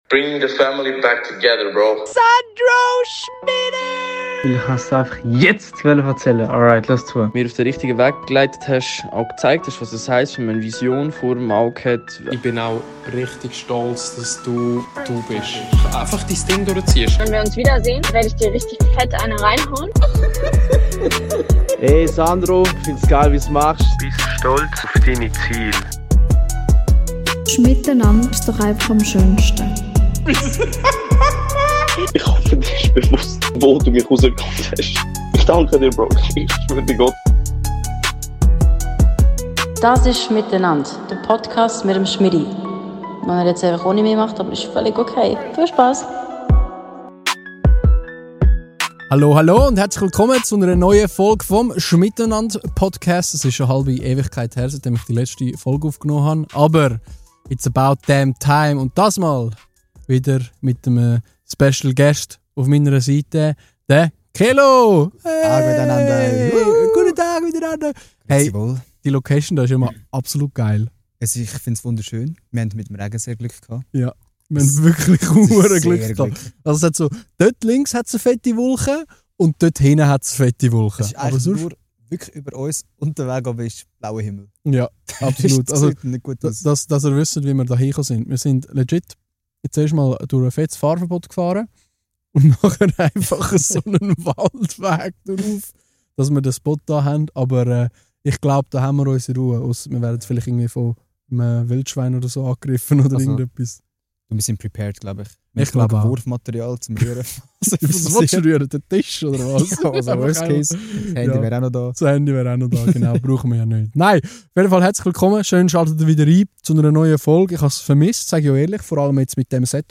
Das ganze imene Ambiente womer so ih dem Podcast nonie gha hend! Losed ine für e Rundi Deeptalk! (mit de eint oder andere technische Störig) Hol dis Popcorn und gnüss de friedlichi Talk!